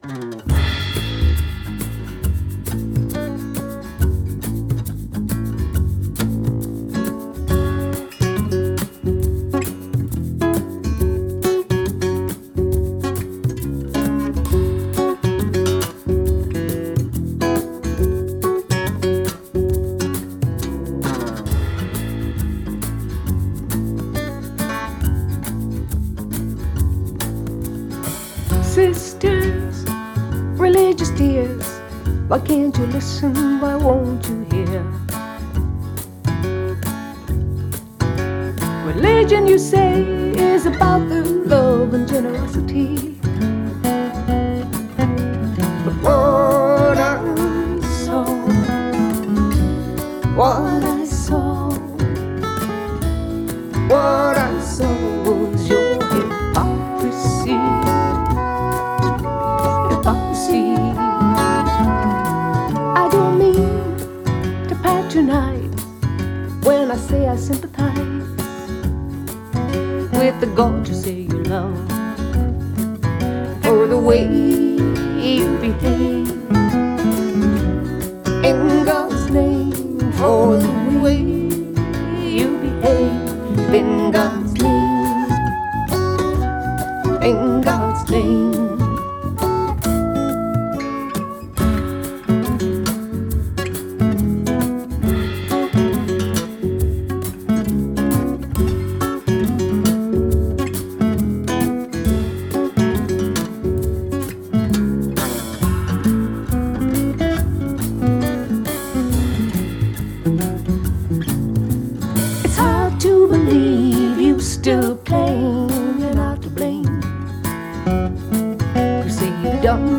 Genre: Folk/Rock